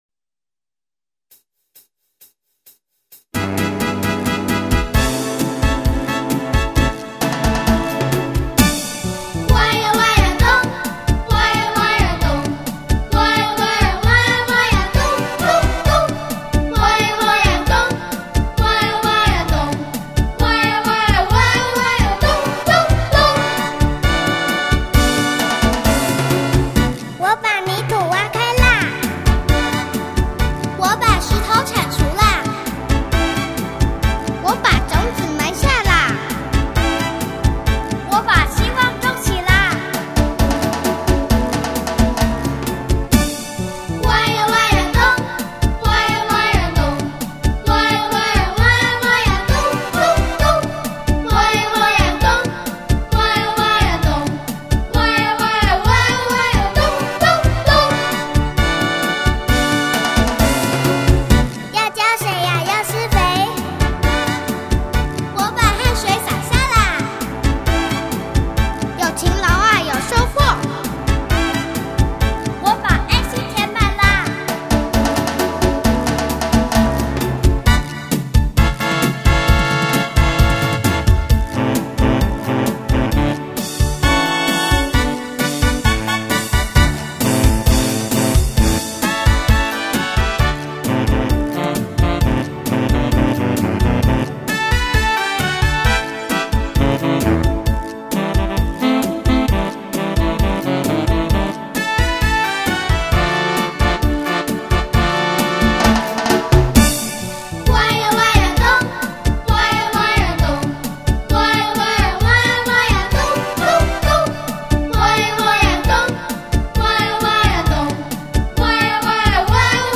Midi演奏
The plot of this suite is that kids and animals are singing together to declare their commitment and belief to reform their homes!
Digging-D-Solo_Choir.mp3